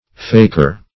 faker \fak"er\, n. [Sometimes erroneously written fakir.]